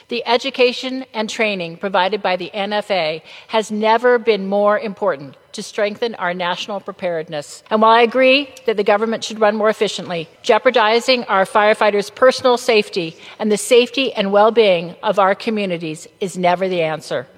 Congresswoman April McClain-Delaney spoke on the House Floor, calling on FEMA to reverse its decision on the closure…